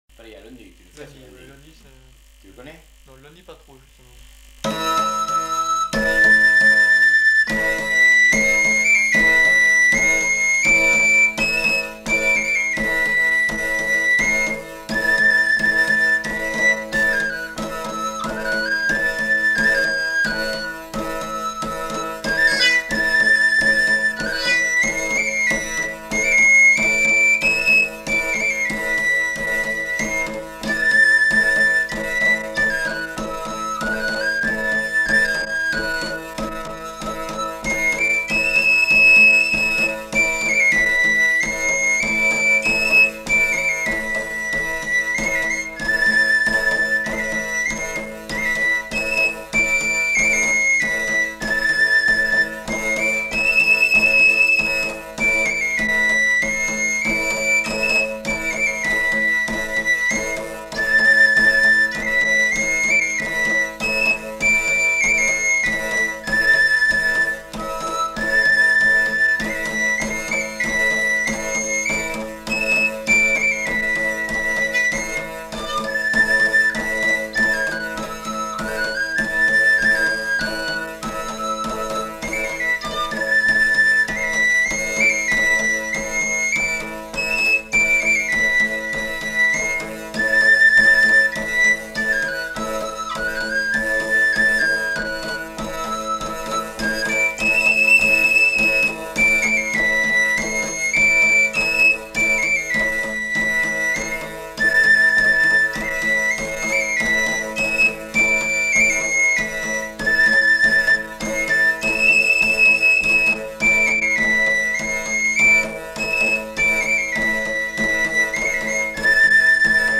Aire culturelle : Béarn
Lieu : Bielle
Genre : morceau instrumental
Instrument de musique : tambourin à cordes ; flûte à trois trous